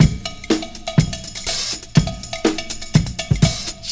• 122 Bpm '00s Breakbeat E Key.wav
Free drum groove - kick tuned to the E note.